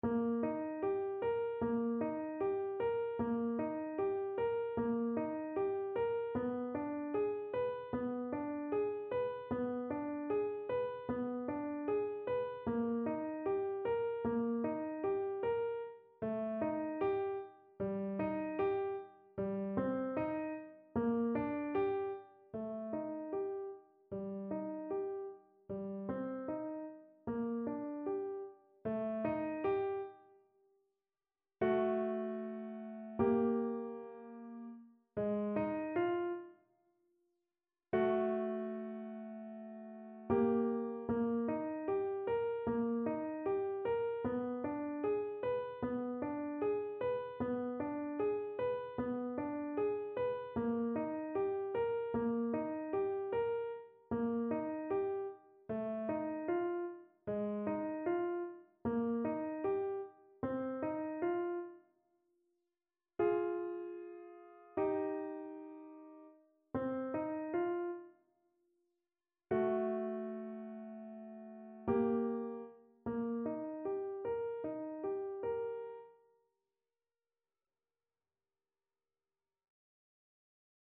Grieg: Arietta (na flet i fortepian)
Symulacja akompaniamentu